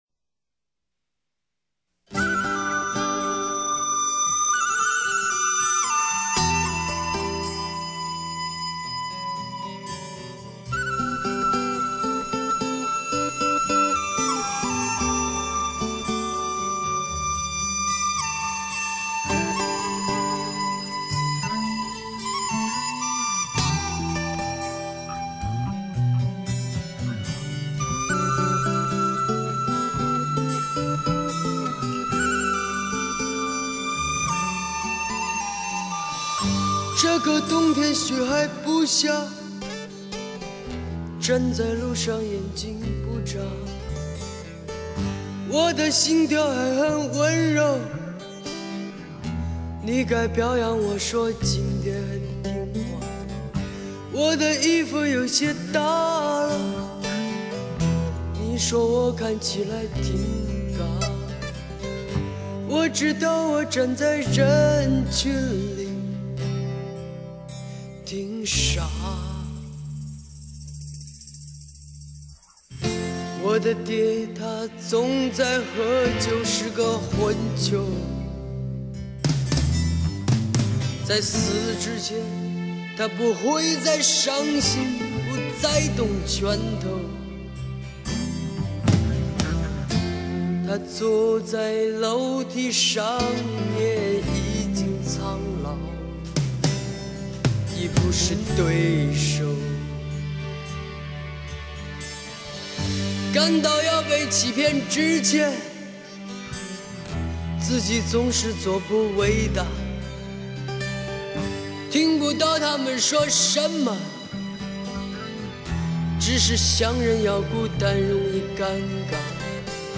有人说他是中国最寂寞的歌手，因为他从小四处飘泊流浪，有人说听他的歌特别感伤，因为歌声浑厚苍茫。